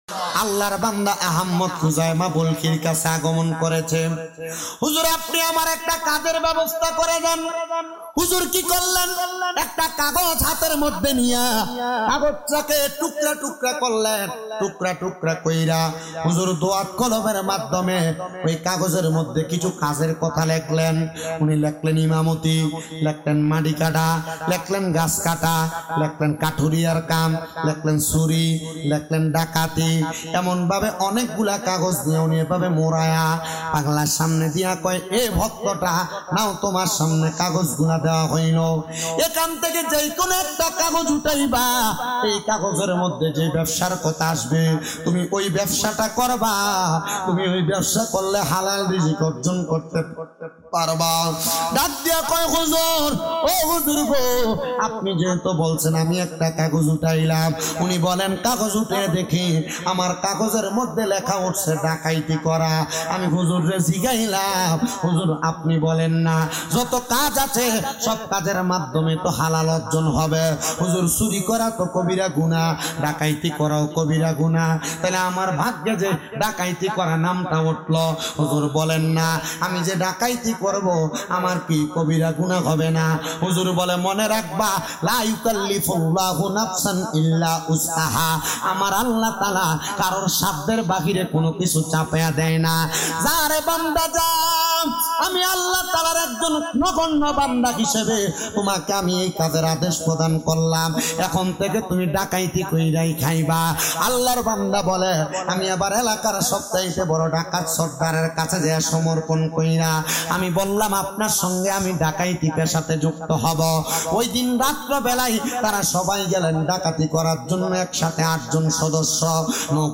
Waz